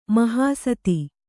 ♪ mahāsati